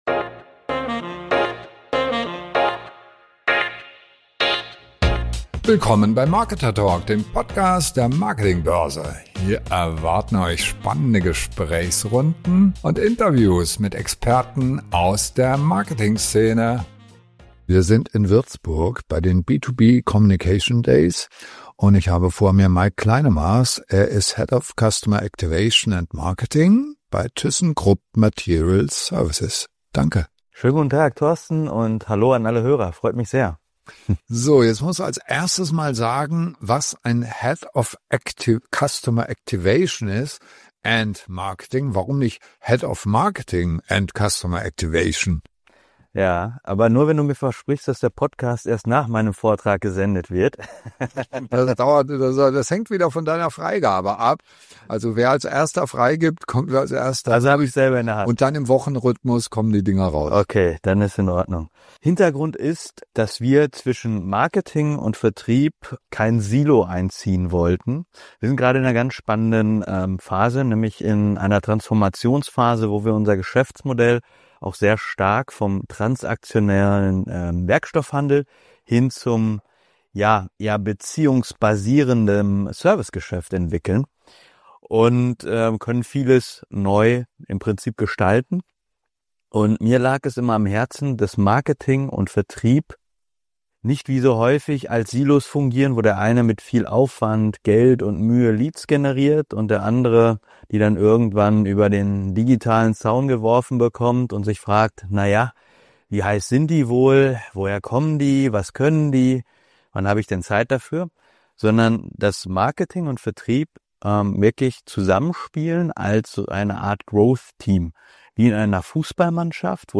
Warum der Mensch im KI-Zeitalter zum entscheidenden Wettbewerbsfaktor wird. Ein spannendes Gespräch über Customer Activation, Account-Based Marketing und die Zukunft von Vertrieb und Marketing.